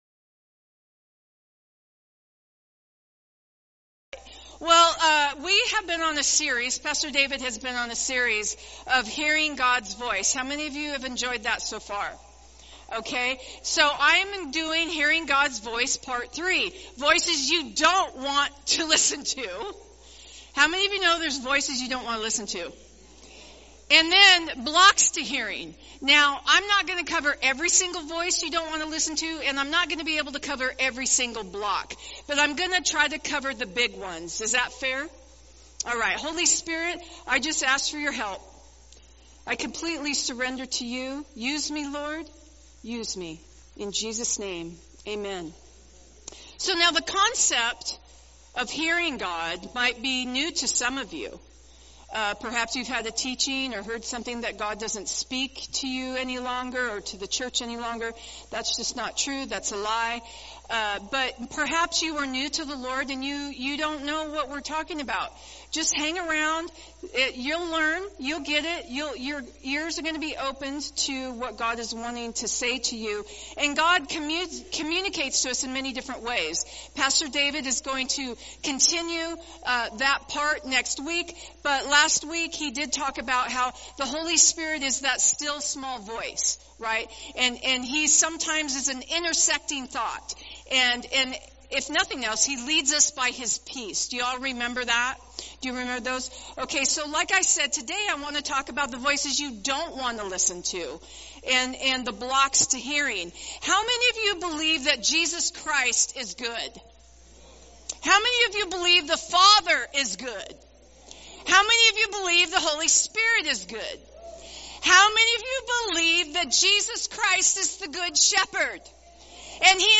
Sermons | Covenant Christian Church